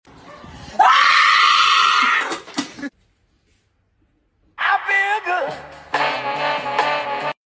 A scream definitely makes you sound effects free download